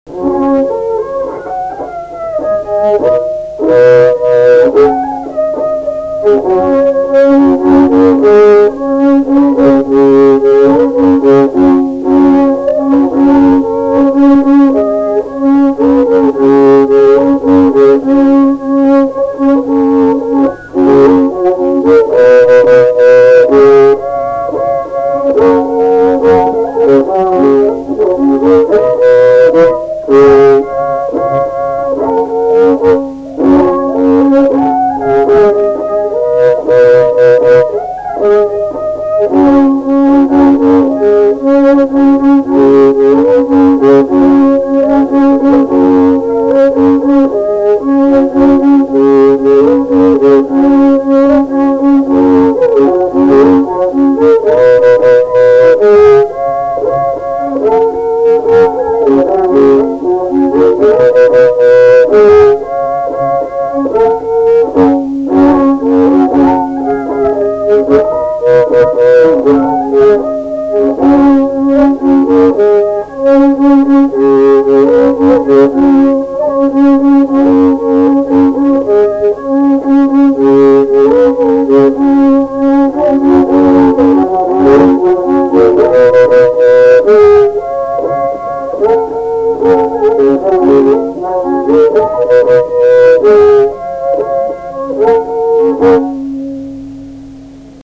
Valsas
šokis